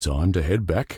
B_hitback.ogg